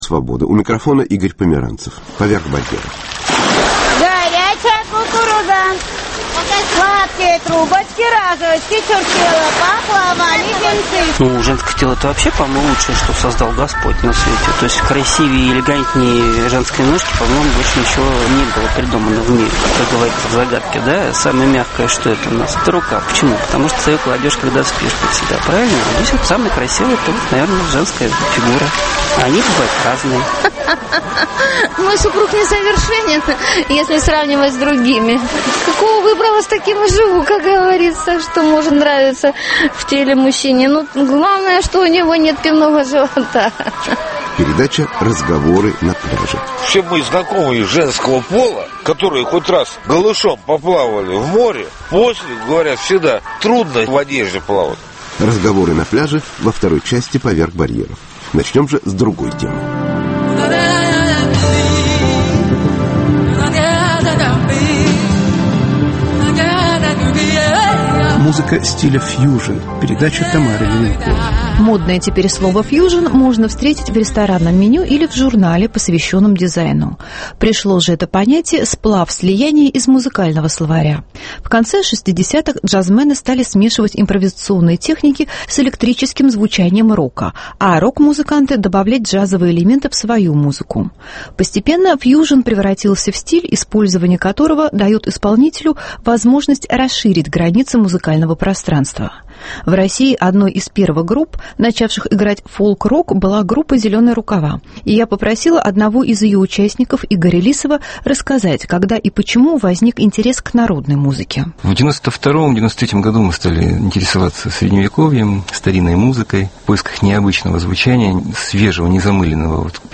Музыка стиля фьюжн.